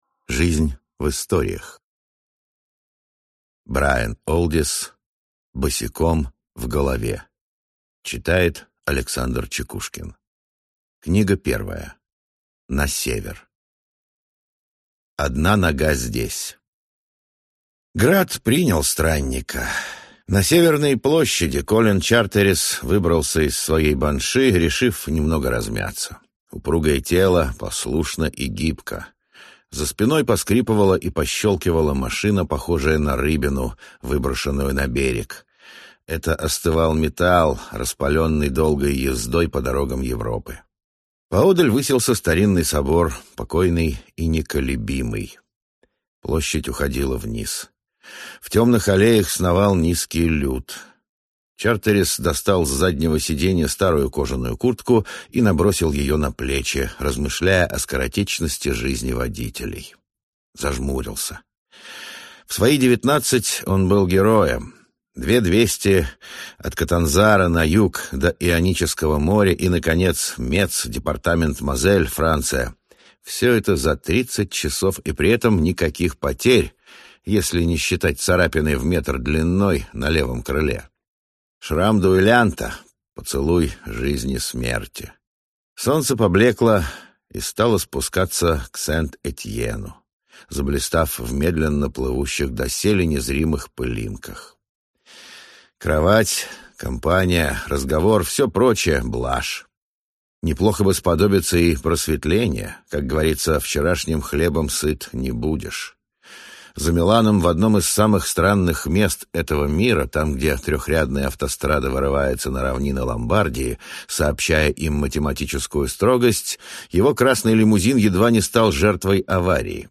Аудиокнига Босиком в голове | Библиотека аудиокниг